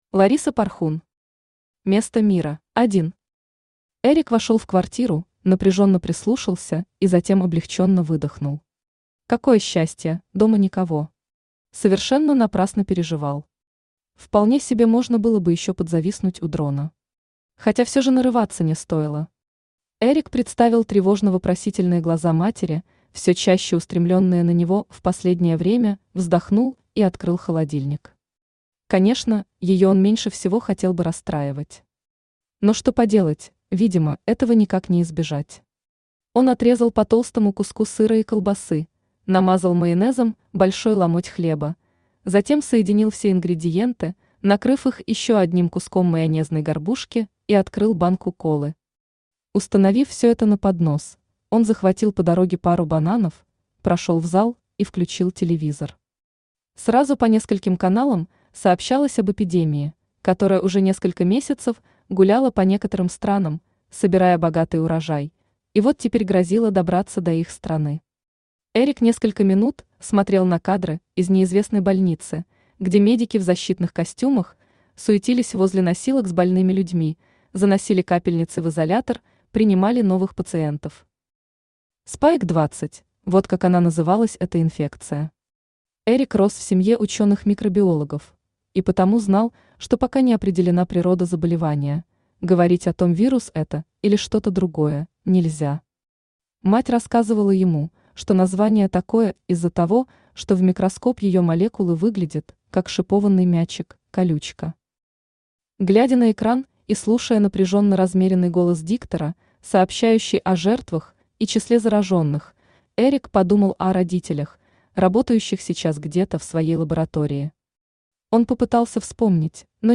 Aудиокнига Место Мира Автор Лариса Порхун Читает аудиокнигу Авточтец ЛитРес.